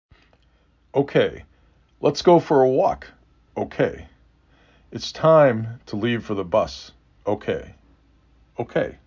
3 Phonemes
O k A